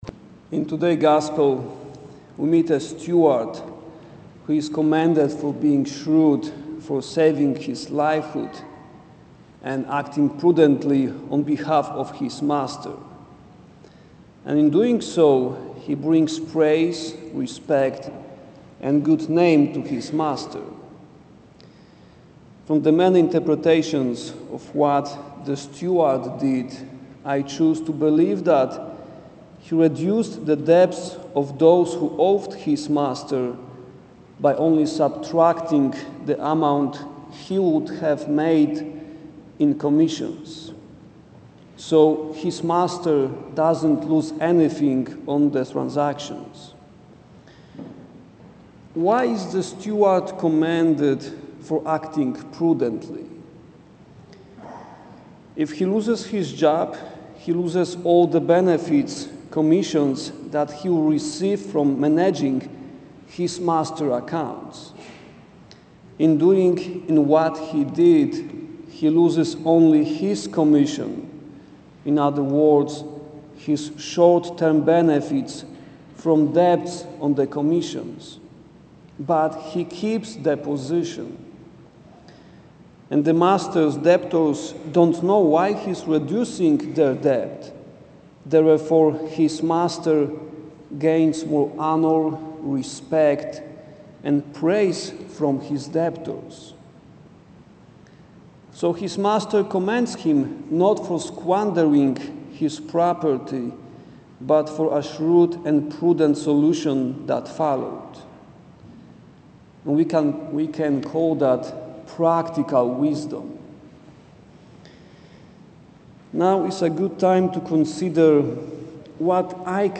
There  is also a version from Adults Mass.